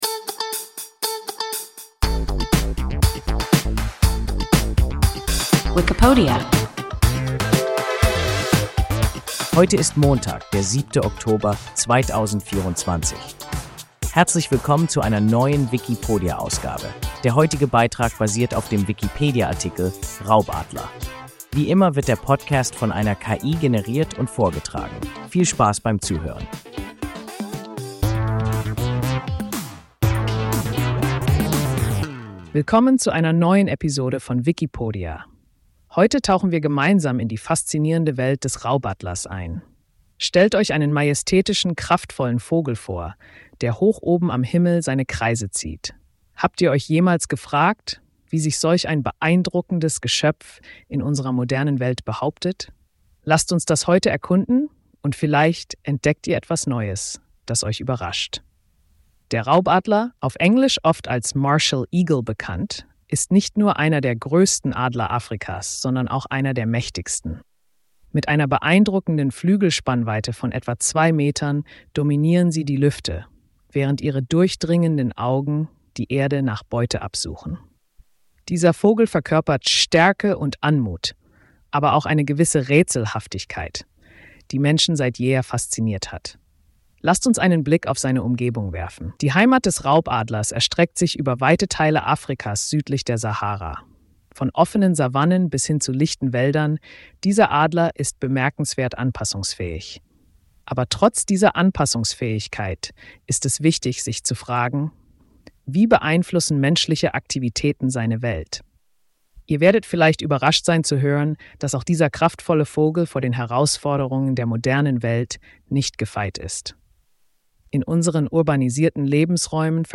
Raubadler – WIKIPODIA – ein KI Podcast